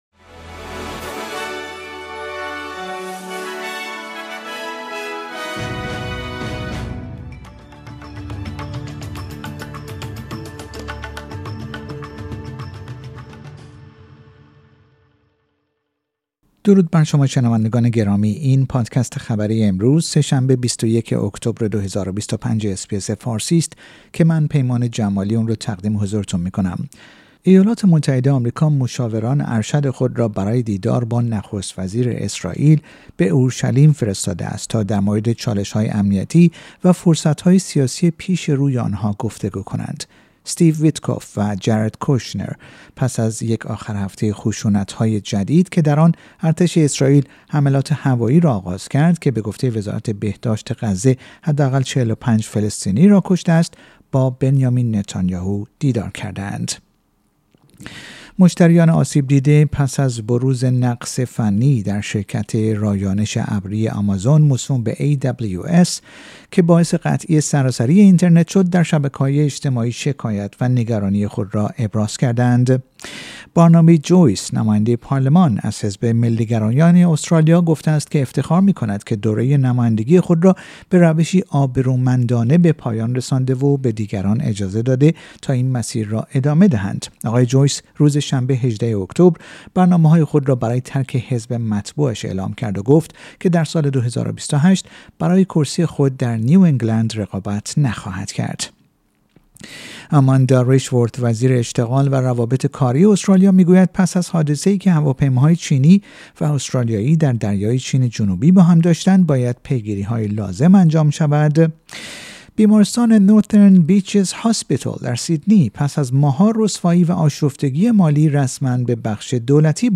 در این پادکست خبری مهمترین اخبار روز سه شنبه ۲۱ اکتبر ارائه شده است.